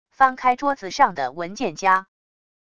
翻开桌子上的文件夹wav音频